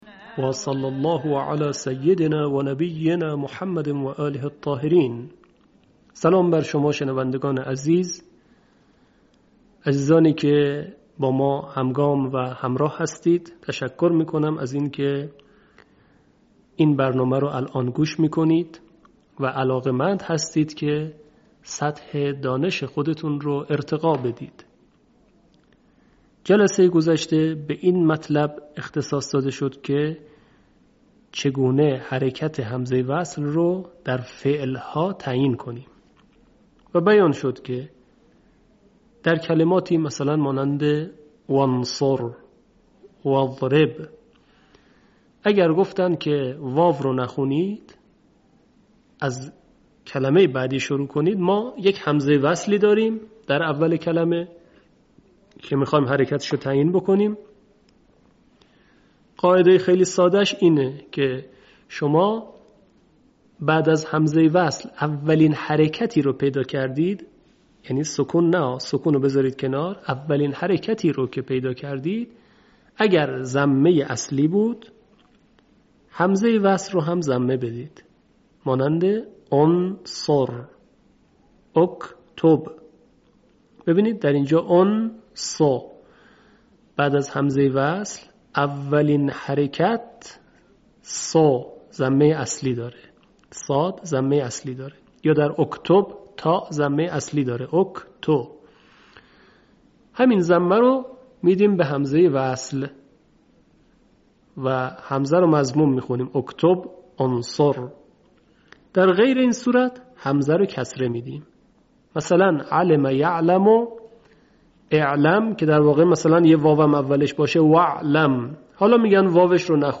صوت | آموزش حرکت همزه وصل در تجوید تحقیقی